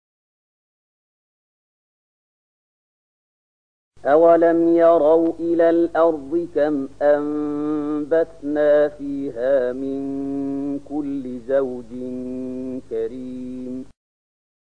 026007 Surat Asy-Syu`araa’ ayat 7 dengan bacaan murattal ayat oleh Syaikh Mahmud Khalilil Hushariy: